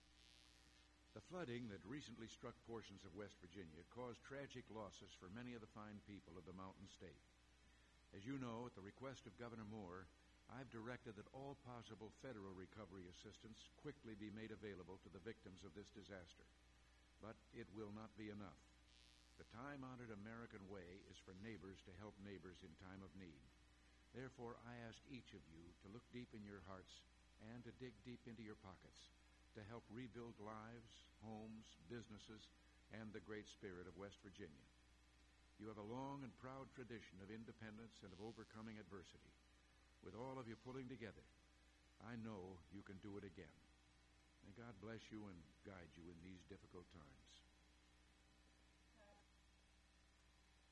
President Reagan’s remarks at Audio Taping on the Flooding In West Virginia, Location – Oval Office